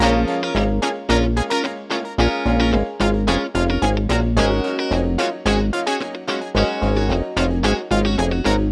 03 Backing PT3.wav